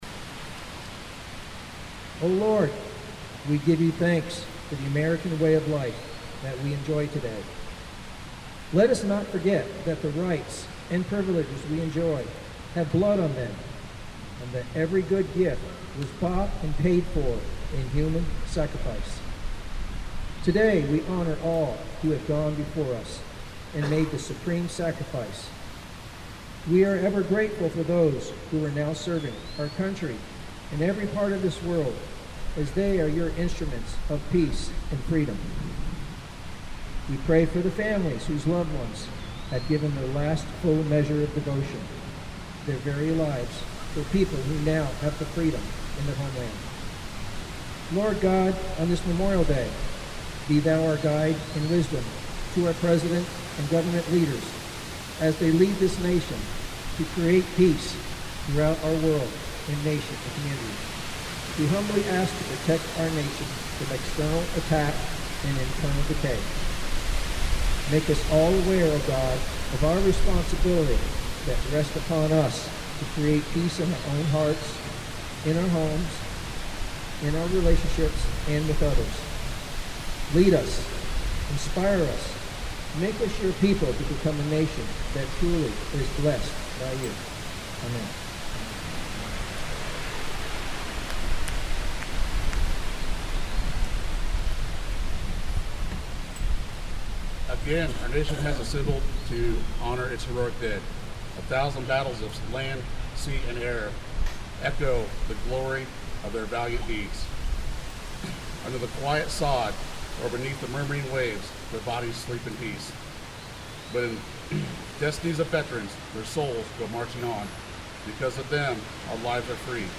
The Sunset Cemetery Memorial Day Service opened around 10 a.m. this morning.
0530-Memorial-Day-Service.mp3